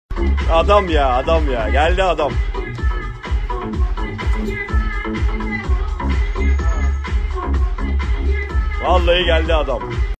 nervous Meme Sound Effect